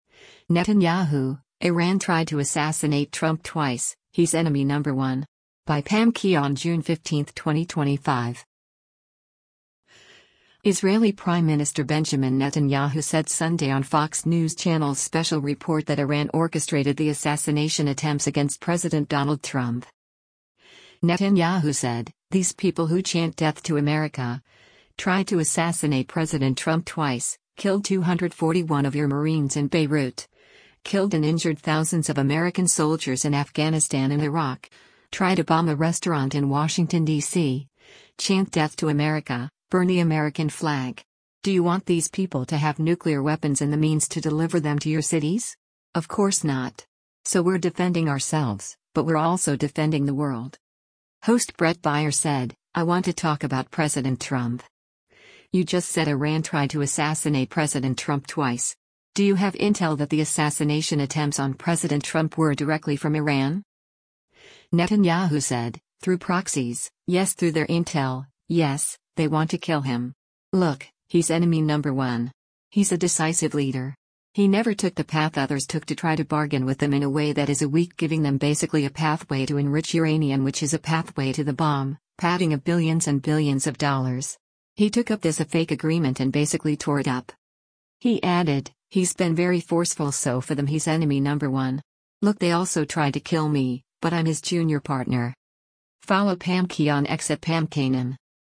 Israeli Prime Minister Benjamin Netanyahu said Sunday on Fox News Channel’s “Special Report” that Iran orchestrated the assassination attempts against President Donald Trump.